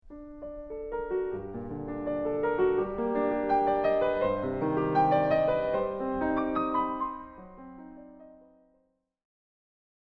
Beethoven then uses a common trick to change the atmosphere right away in the beginning of the development, namely by playing the beginning again, but in minor: